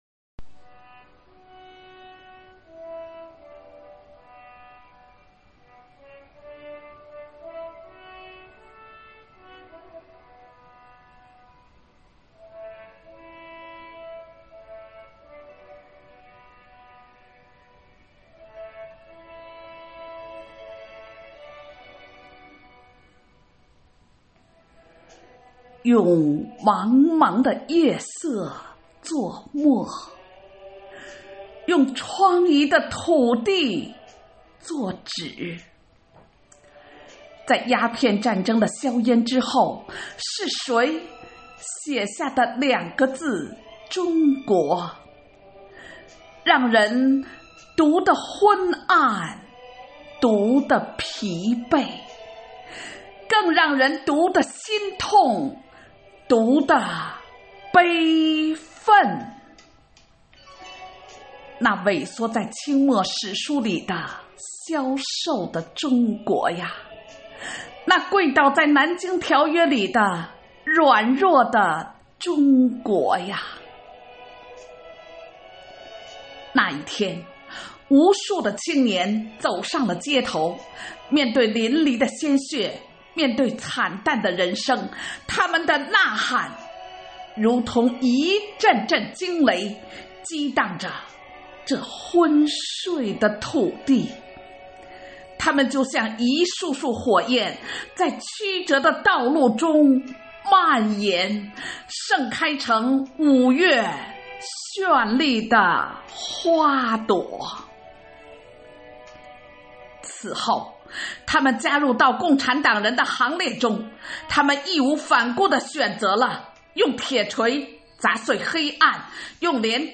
“党在我心中，喜迎二十大”主题朗诵会